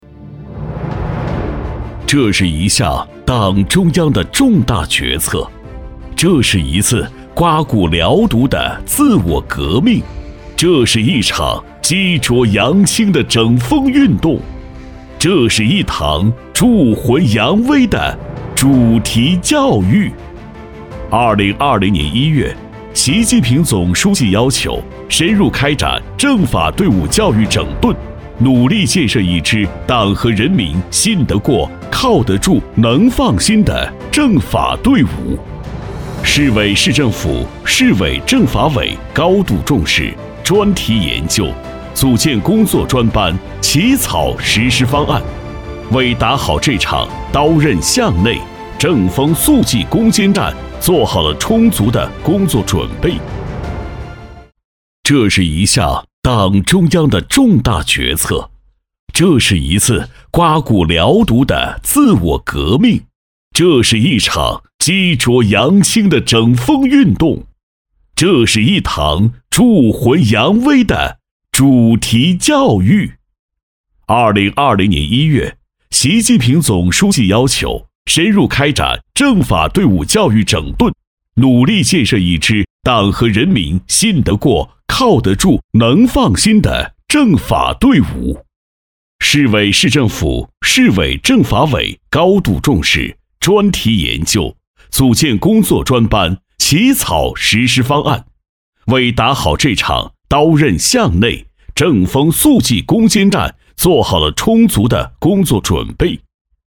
淘声配音网，专题，宣传片配音，专业网络配音平台